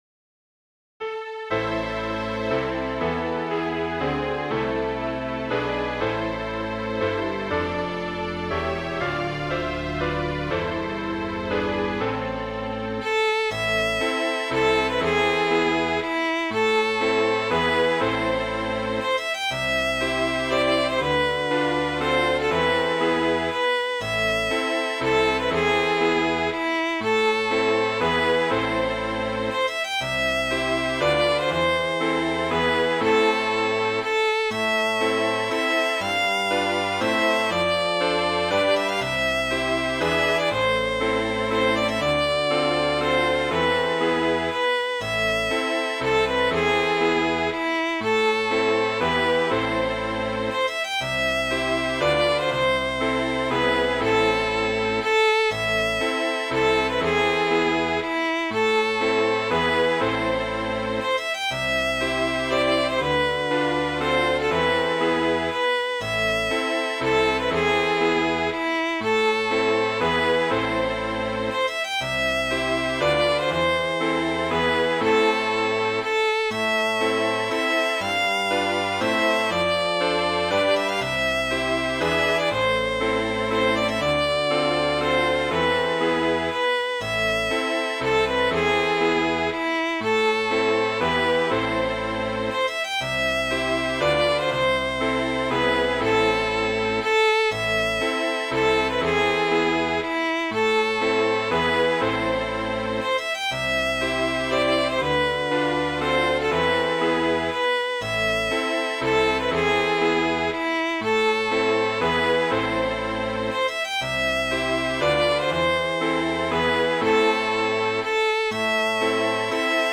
Midi File, Lyrics and Information to Lord Gregory
gregory.mid.ogg